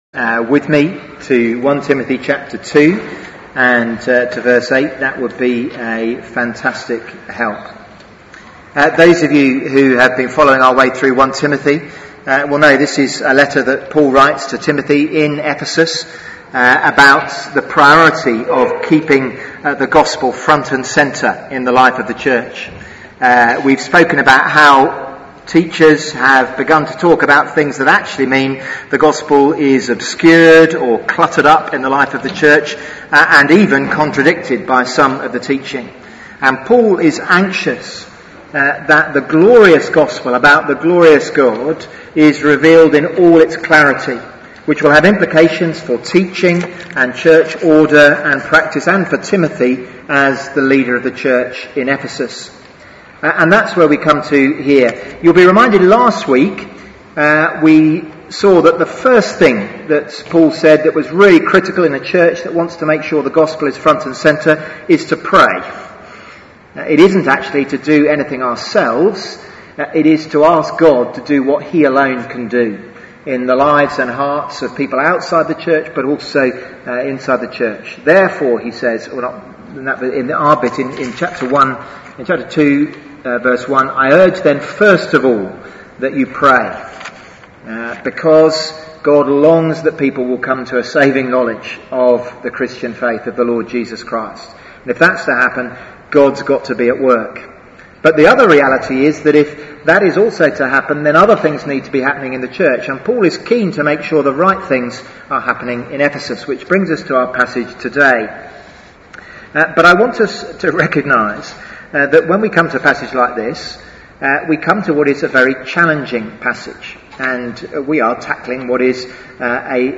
Theme: Leadership and the Gospel Sermon